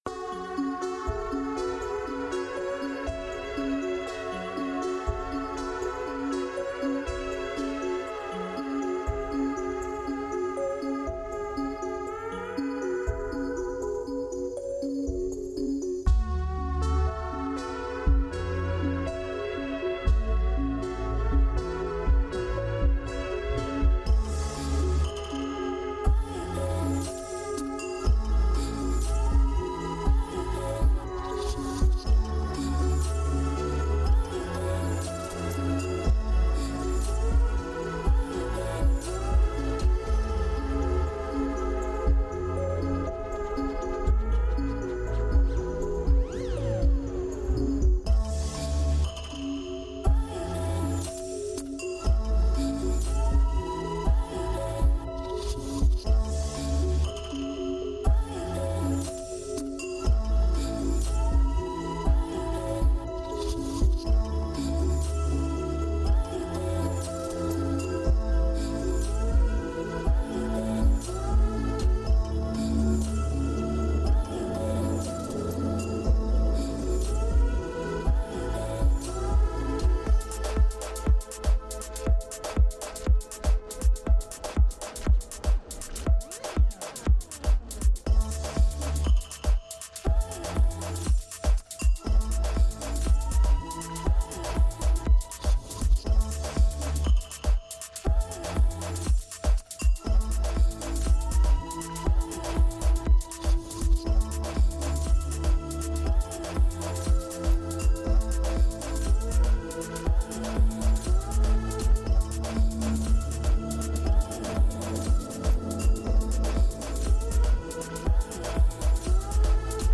Tempo 119BPM (Moderato)
Genre Dark Trap Chill
Type Adlib-strumental
Mood Chill